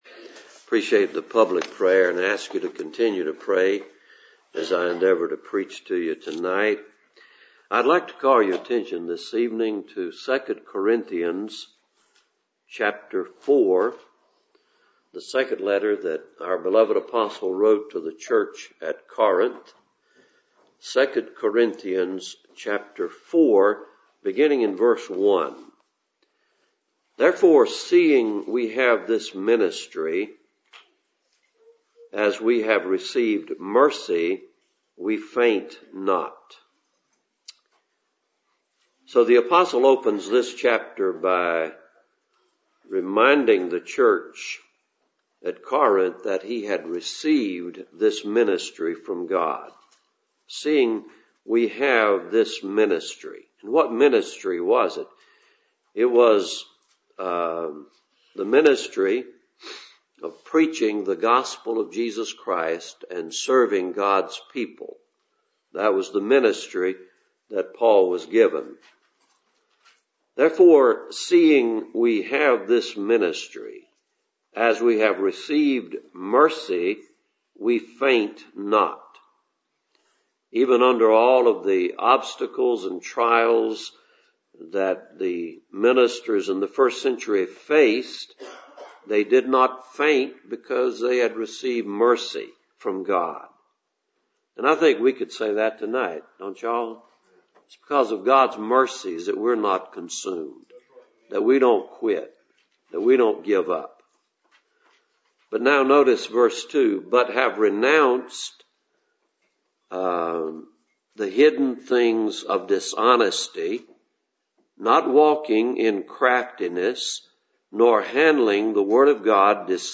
2 Corinthians 4:1-6 Service Type: Cool Springs PBC Sunday Evening Therefore seeing we have this ministry